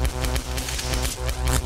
LightningLoop.wav